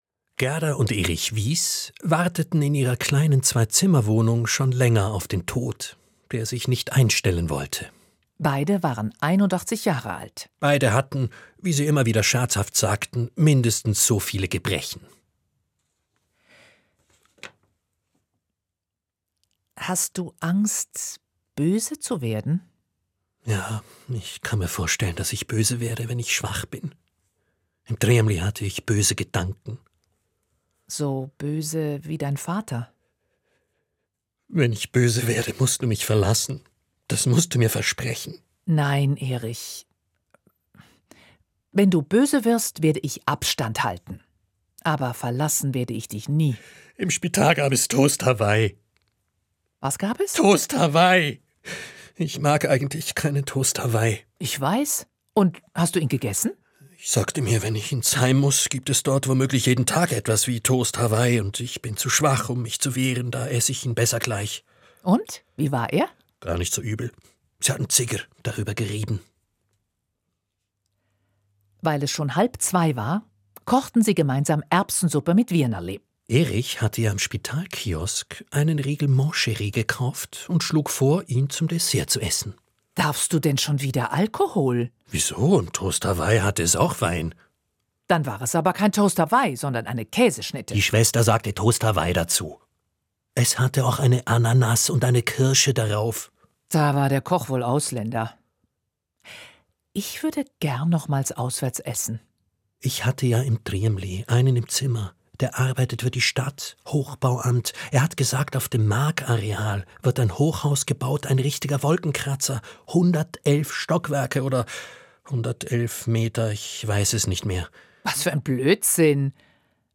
Erich und Gerda, Szenische Lesung – Kultur am Montag 2026
Trailer (Audio)